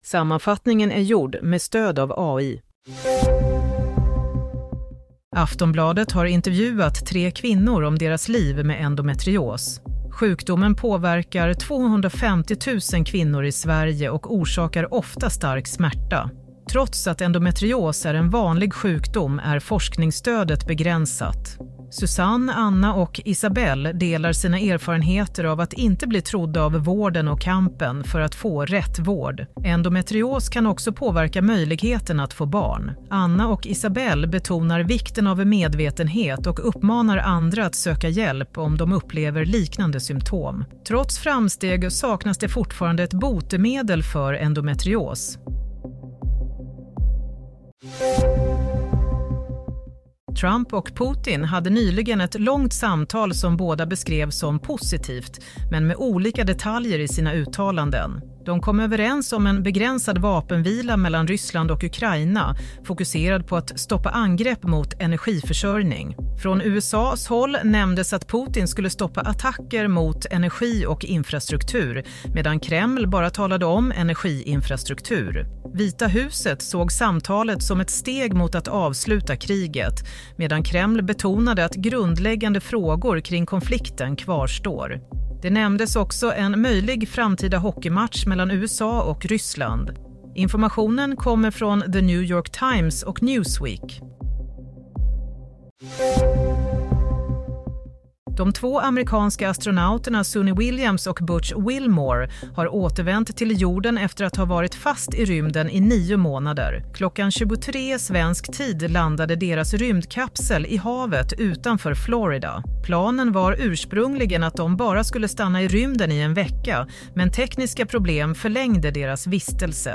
Nyhetssammanfattning 19 mars klockan 07.30
Sammanfattningen av följande nyheter är gjord med stöd av AI.